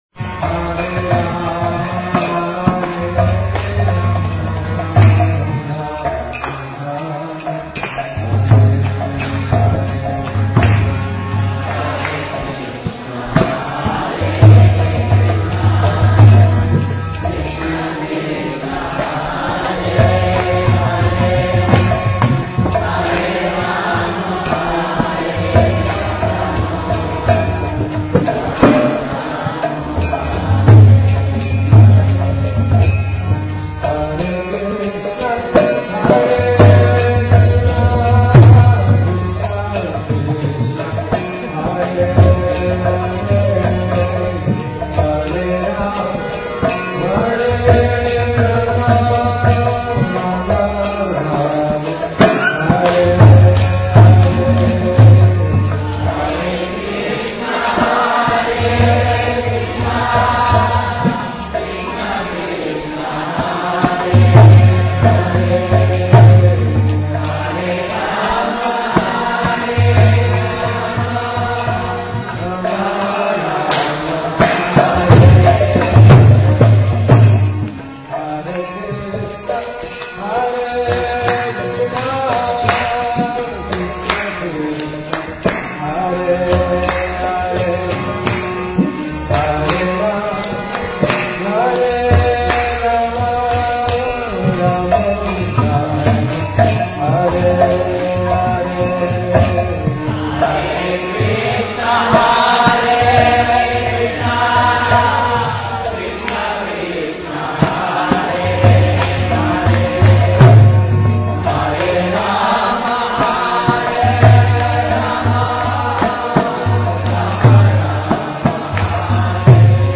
Kirtana